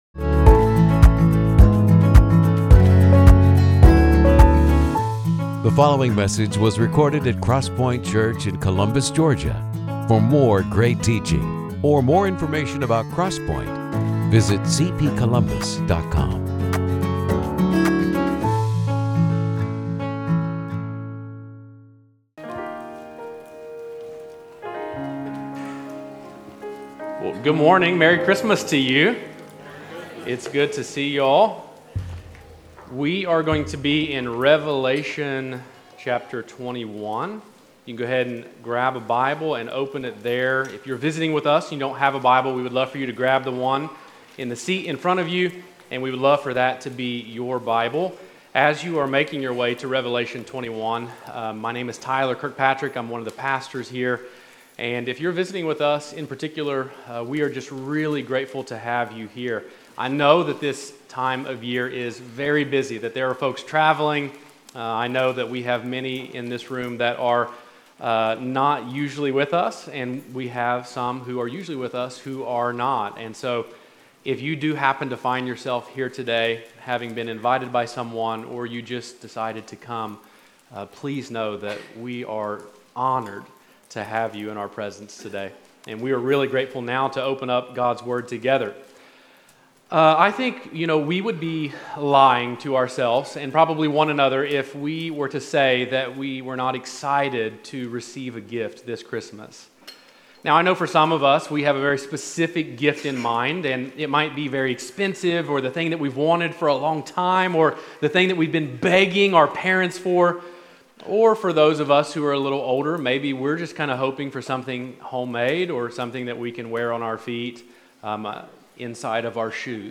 The sermons of CrossPointe Church in Columbus, Ga.